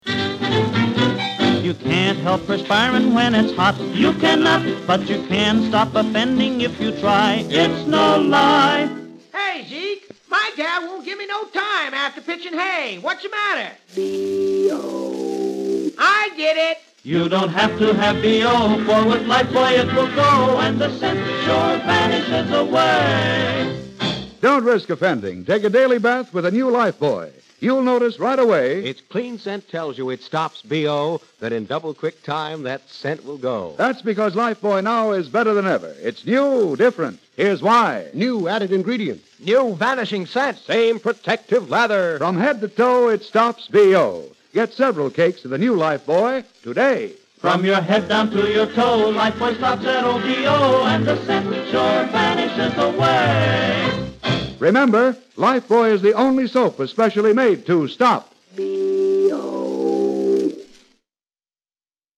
NOW...for the vintage stuff!!!! this material is taken from my private collection of 1940's 16 inch radio transcriptions! these records were what those golden age of radio shows were usually recorded on, as this was before tape!!!! these were HUGE!!! 4 inches wider than a LP, but could only hold about 15 mins a side!!!
.Here's something that might be rare.....circa World War Two, this is a campaign for the Canadian Red Cross as a promotion to promote giving blood....but listen to the announcer in here....it's LORNE GREENE! known as the voice of doom on canadian radio during the war, lorne acted in canadian TV, founded a broadcast school in toronto, and eventually moved to Hollywood to appear as "Pa Cartright"  for years in the american western "Bonanza" among other roles such as "Commander Adama" in Battlestar Galactica. this is a example I assume of his freelance work during the war!